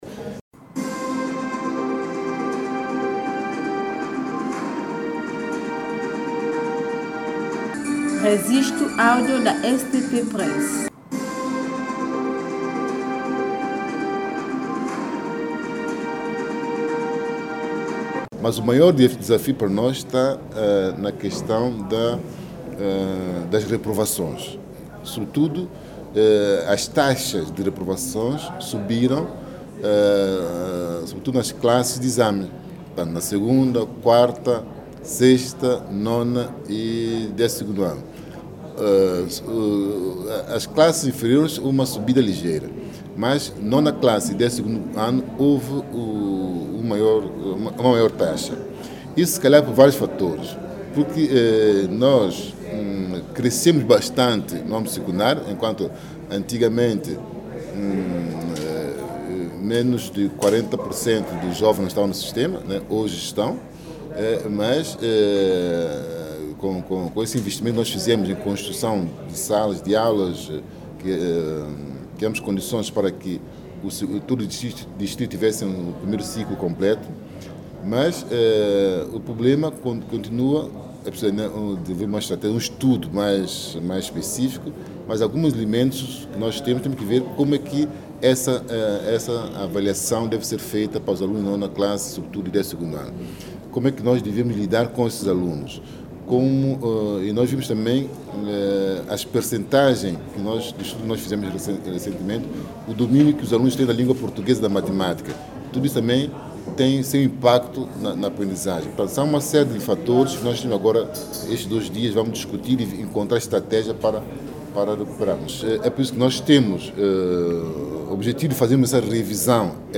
Olinto Daio, o titular da pasta da Educação, Cultura, Ciência e Comunicação fez estas declarações hoje em cerimónia de abertura da “Revisão Sectorial Conjunta em São Tomé e Príncipe”,: um encontro reflexão entre os profissionais da educação e parceiros internacionais visando a adopção de nova política do ensino-2018 à 2030.
Declarações de Ministro da Educação Olinto Daio